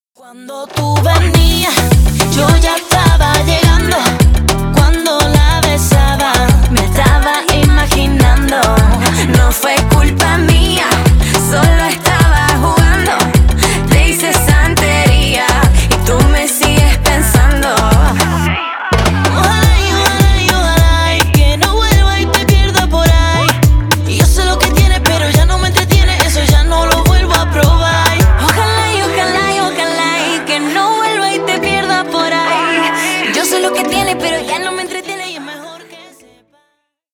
• Качество: 320 kbps, Stereo
Поп Музыка
латинские